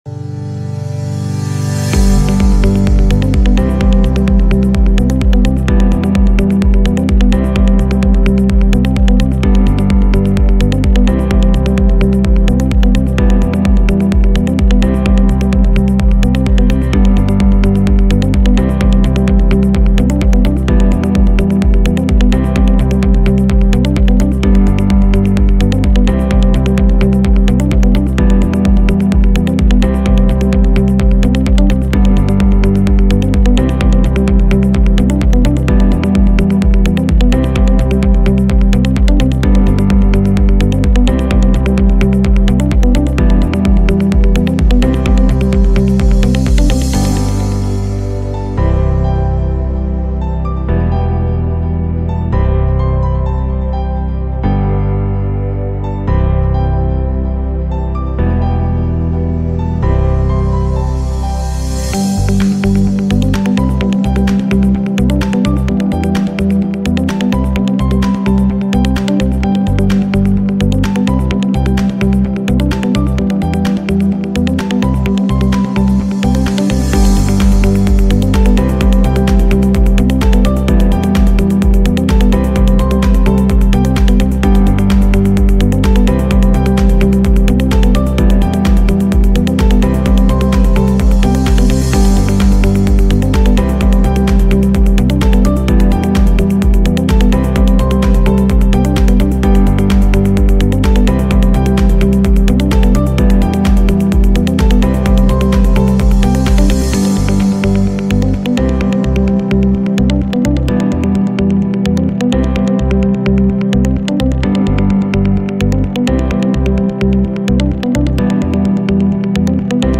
Uplifting.mp3